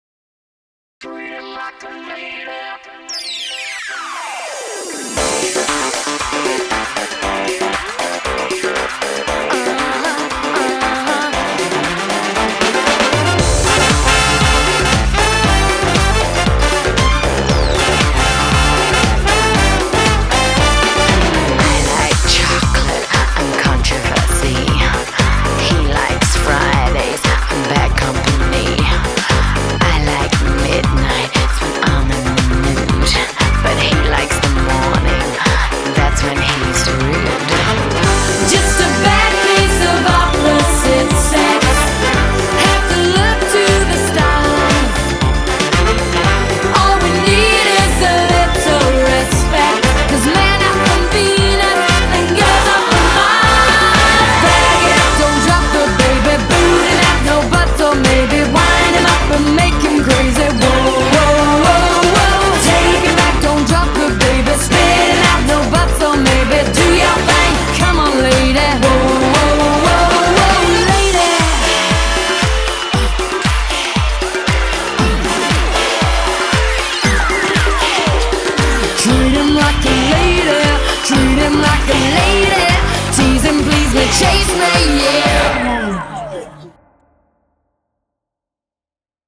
BPM0-116
Audio QualityMusic Cut